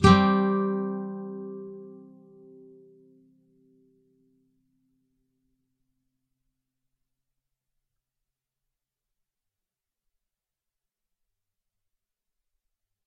尼龙吉他开放性和弦 " Aadd2 full up
描述：标准开放式Aadd2和弦。与A大调相同，只是B（第二）弦是开放的。上弦。如果这些样本有任何错误或缺点，请告诉我。
标签： 音响 清洁 吉他 尼龙吉他 开弦
声道立体声